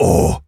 gorilla_hurt_07.wav